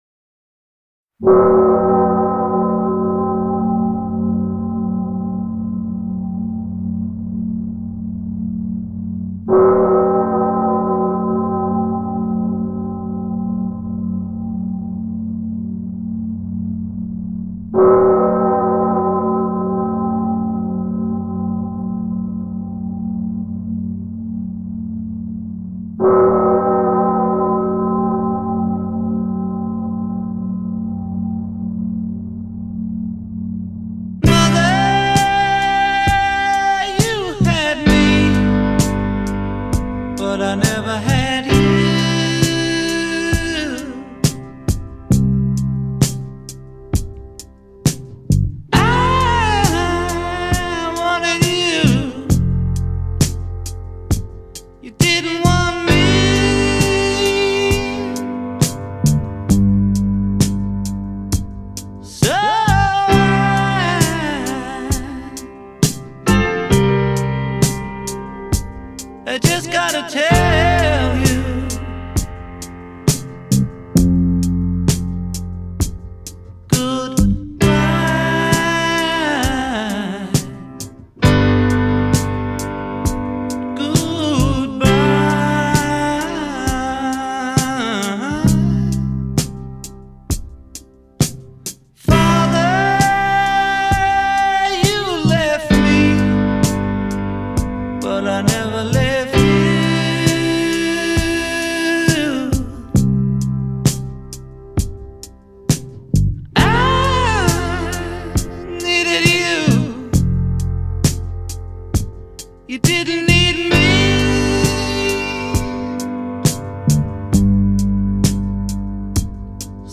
Rock, Avant-Pop, Psychedelic Rock, Singer-Songwriter